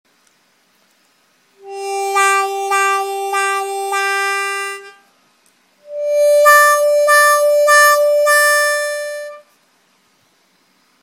Эффект «вау-вау» (англ. wah-wah).
При помощи этого эффекта вы сможете издавать звуки на губной гармошке, похожие на произношение звуков «уау», «вау».
wah-wah.mp3